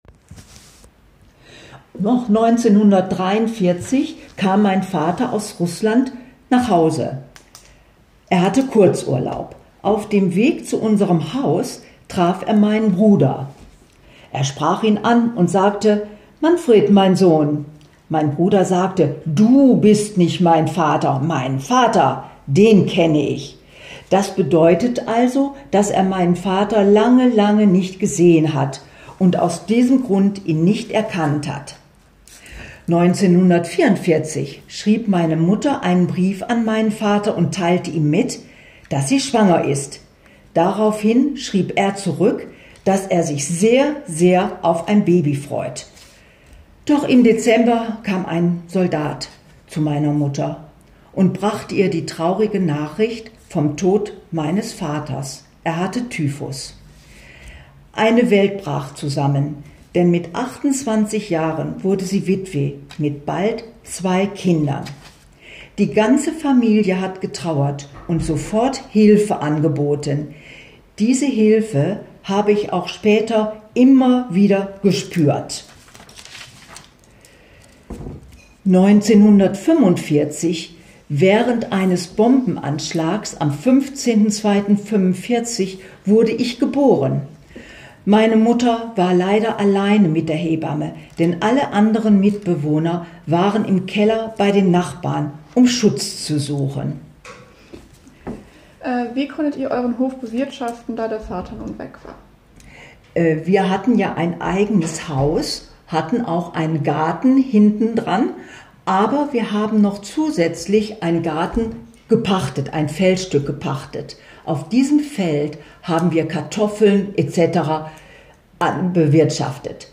The interviews are given in the original language or a transliteration of it with preservation of national, regional and individual speech peculiarities.
Interview.m4a